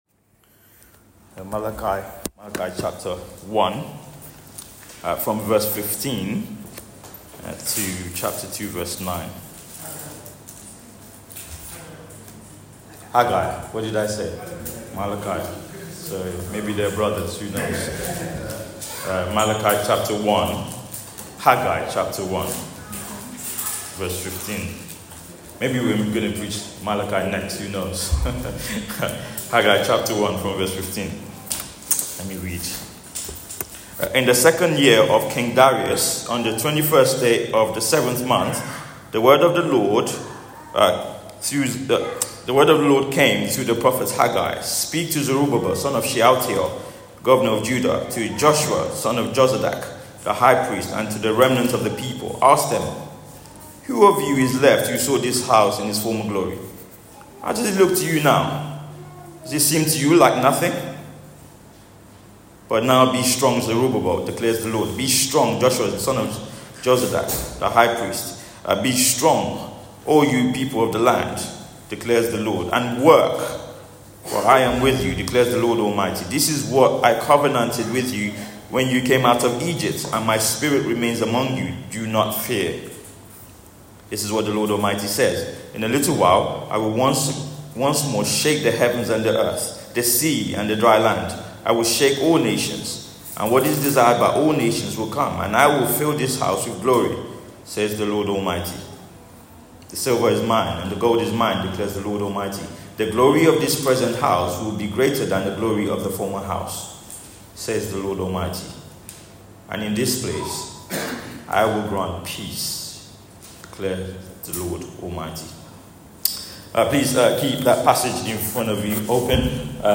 Theme: Building when it's tough Sermon